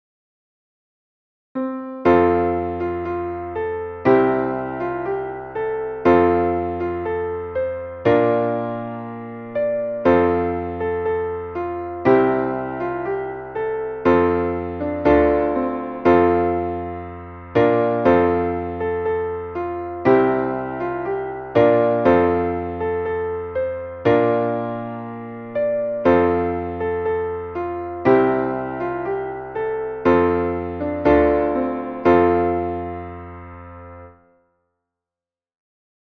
Traditionelles schottisches Volkslied